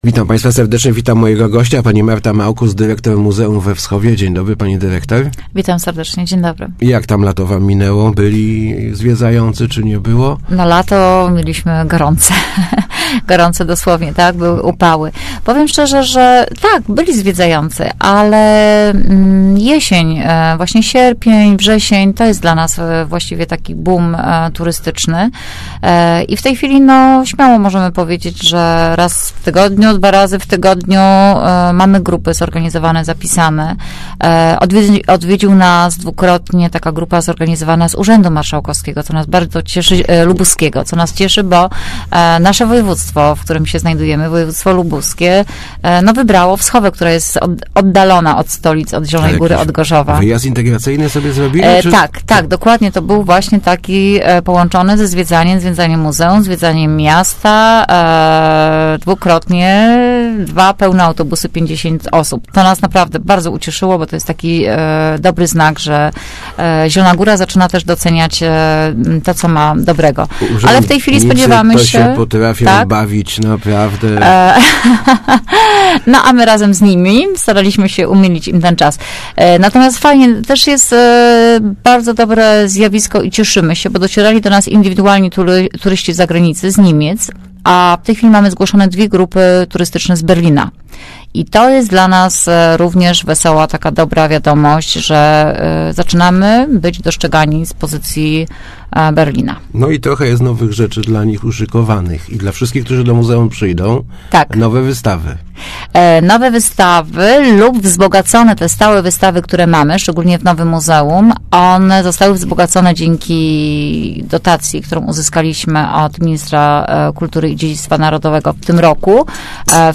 Start arrow Rozmowy Elki arrow Skarb w muzeum?